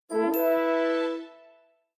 The Landscape Logon sound button is a popular audio clip perfect for your soundboard, content creation, and entertainment.